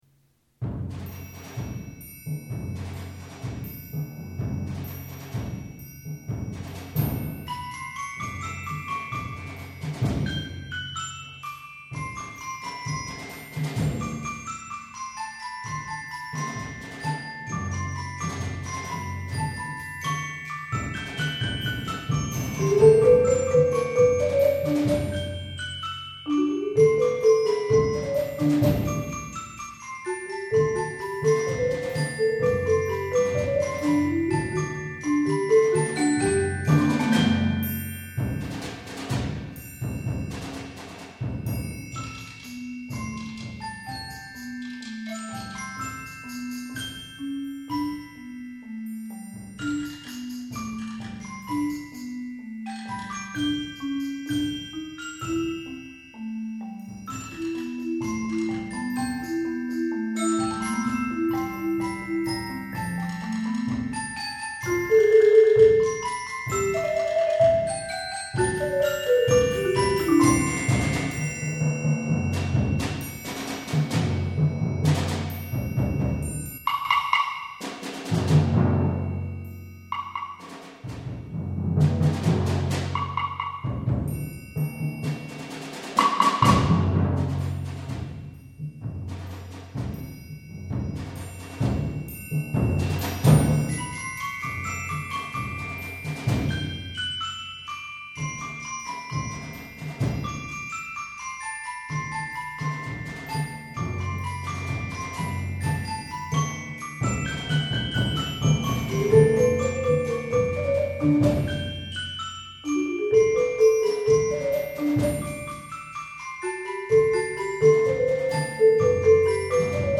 Genre: Percussion Ensemble
# of Players: 5-8
Percussion 1 (bells and/or xylophone, woodblock)
Percussion 2 (optional 4-octave marimba or vibraphone)
Percussion 3 (triangle)
Percussion 4 (snare drum)
Percussion 5 (bass drum)
Percussion 6 (4 Timpani)